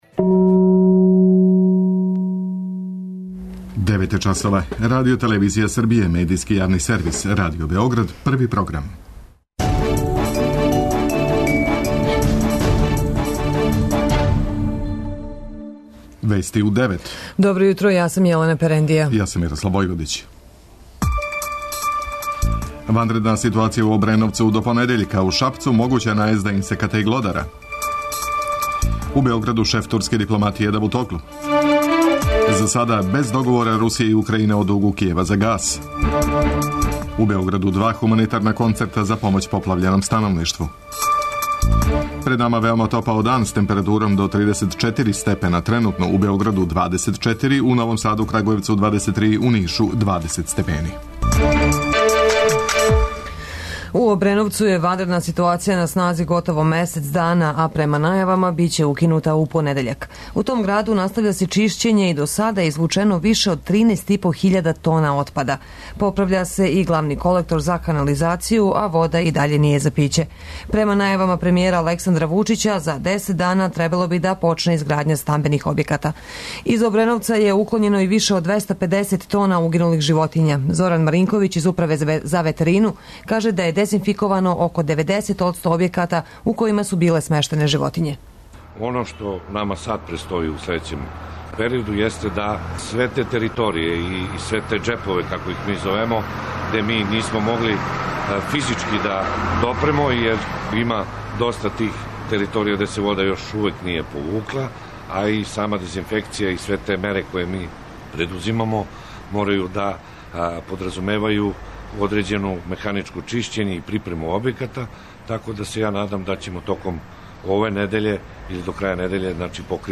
Уредници и водитељи